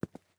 ES_Footsteps Concrete 4.wav